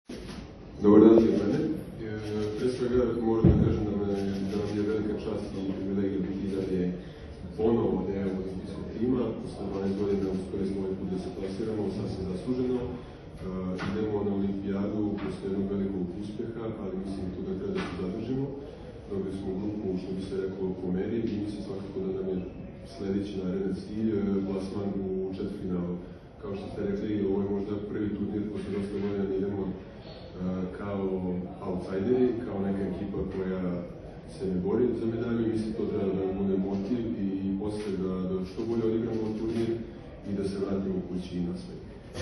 Muška seniorska reprezentacija Srbije otputovala je danas letom iz Beograda u Pariz gde će igrati na XXXIII Olimpijskim igrama 2024. Pred sam polazak je na beogradskom aerodromu “Nikola Tesla” organizovana konferencija za novinare.
Izjava Marka Podraščanina